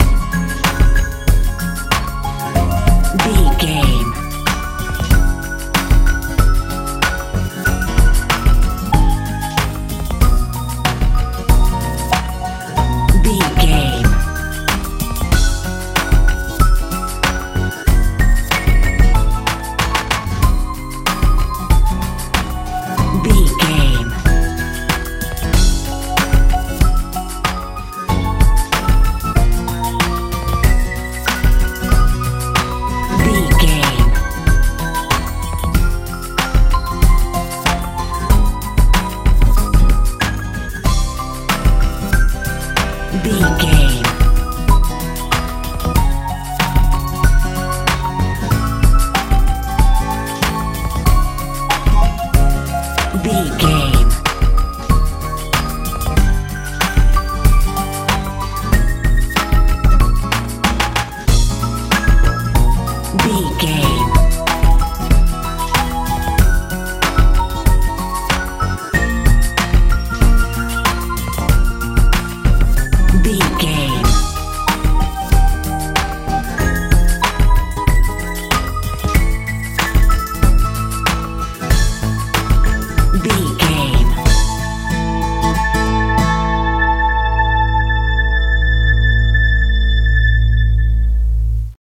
modern pop feel
Ionian/Major
C♯
happy
fun
acoustic guitar
synthesiser
bass guitar
drums
80s
90s
mellow